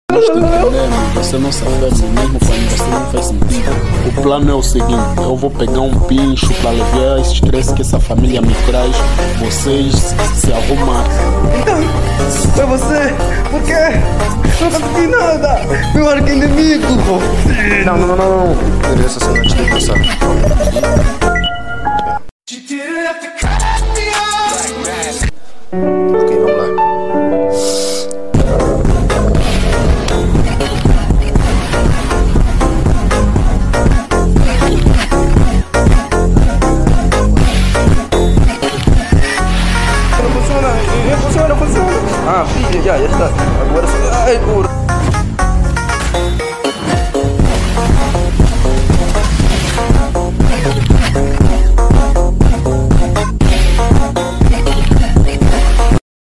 DUBLAGEM ANGOLANA: INCRÍVEL MUNDO